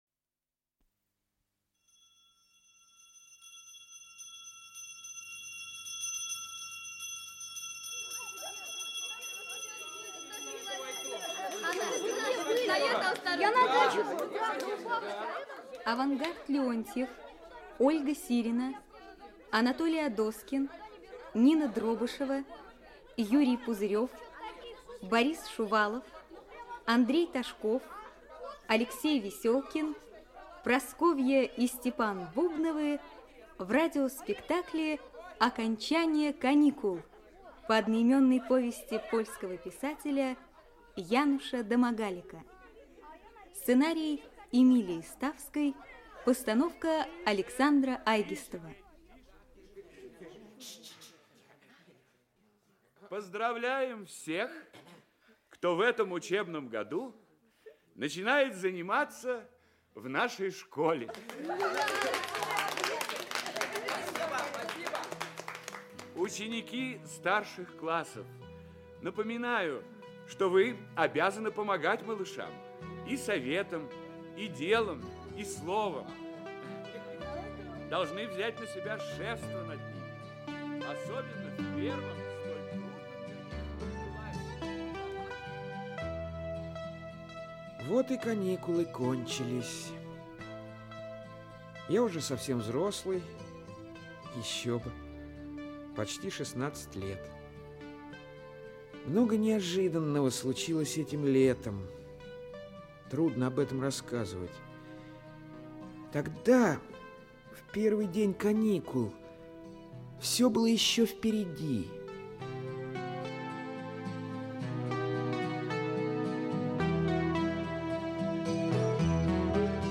Аудиокнига Окончание каникул. Часть 1 | Библиотека аудиокниг